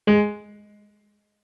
MIDI-Synthesizer/Project/Piano/36.ogg at 51c16a17ac42a0203ee77c8c68e83996ce3f6132